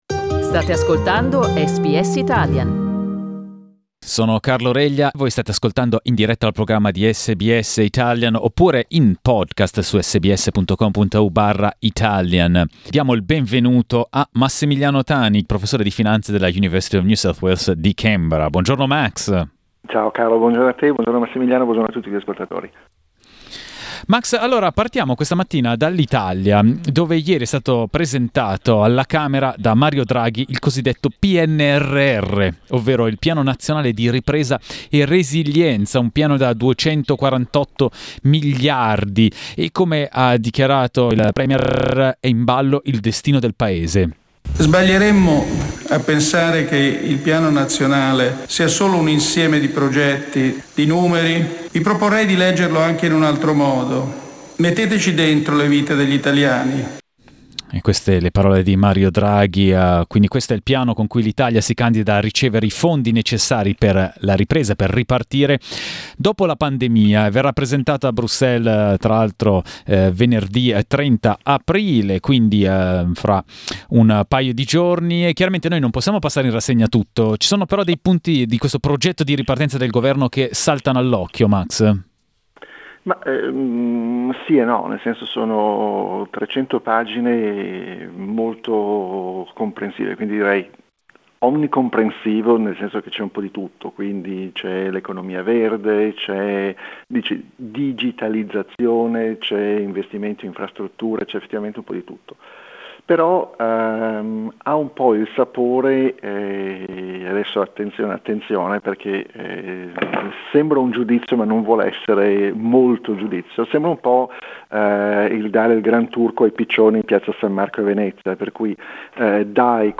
Ascolta l'intervista: LISTEN TO "Recovery", Camera e Senato approvano il Piano SBS Italian 09:58 Italian Le persone in Australia devono stare ad almeno 1,5 metri di distanza dagli altri.